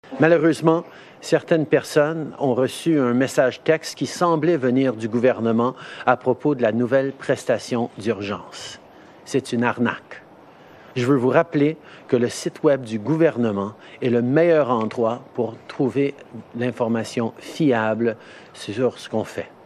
Lors de son point de presse quotidien, le premier ministre Justin Trudeau a déploré les tentatives de certains de frauder des personnes en situation financière précaire.